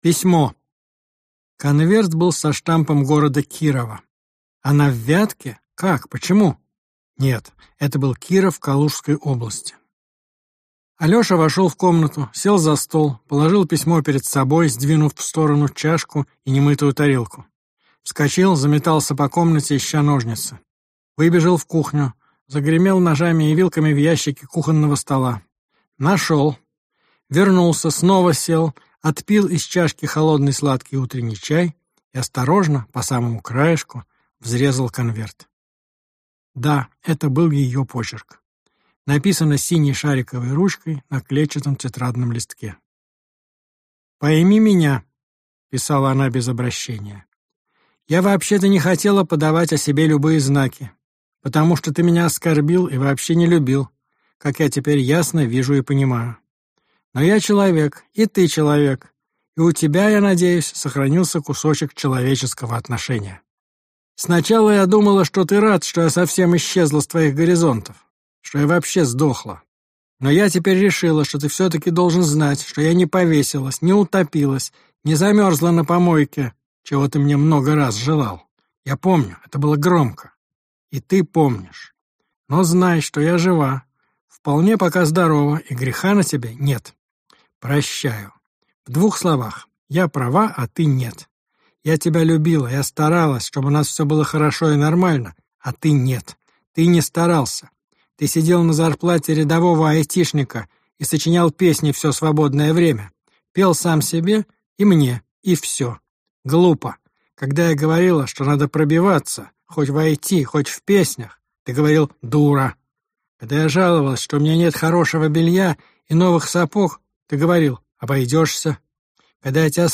Аудиокнига Как не умереть от любви. 22 урока | Библиотека аудиокниг
Aудиокнига Как не умереть от любви. 22 урока Автор Денис Драгунский Читает аудиокнигу Денис Драгунский.